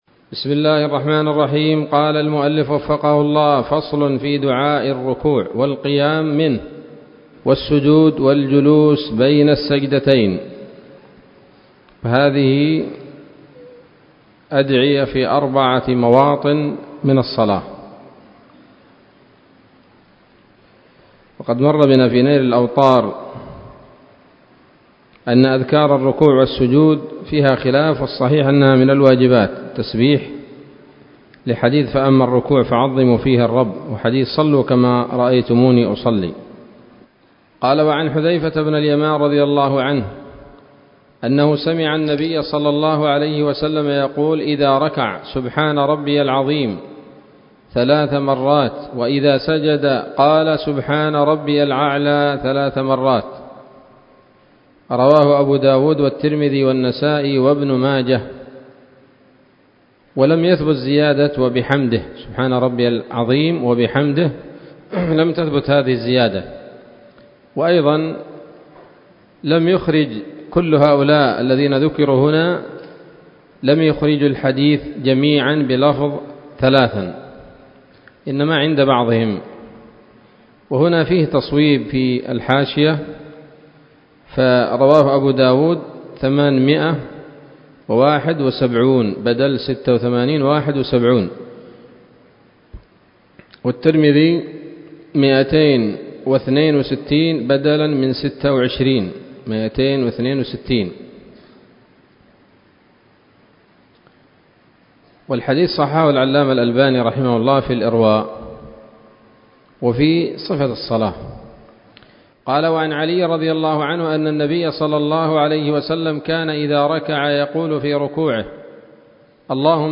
الدرس الخامس والعشرون من رياض الأبرار من صحيح الأذكار